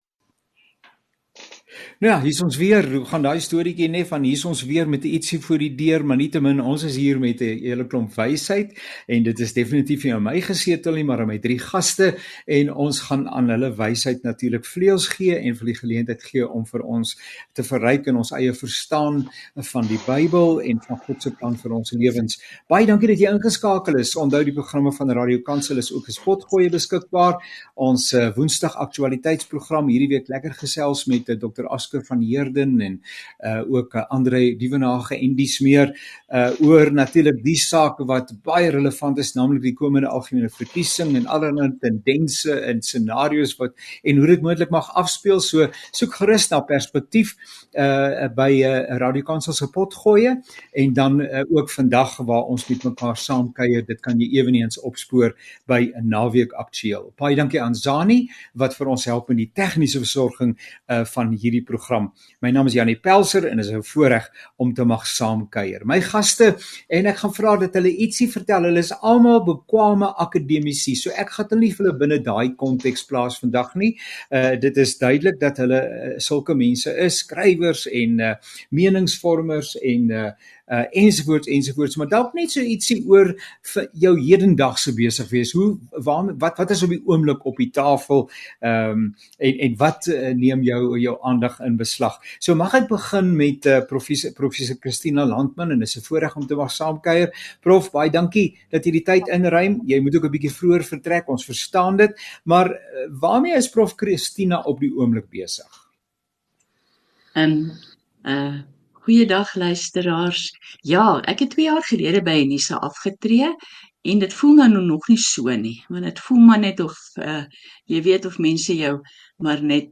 gesels oor die sin en wese van geloof.